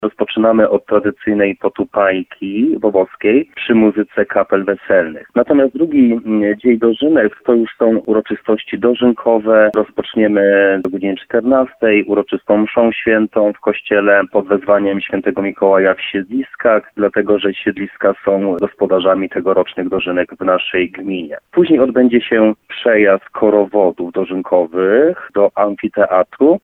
– Dużo będzie się działo już w sobotę, kiedy rozpoczniemy świętowanie o 17.00 – również z okazji zakończenia wakacji – mówi burmistrz Bobowej Marcin Wąs.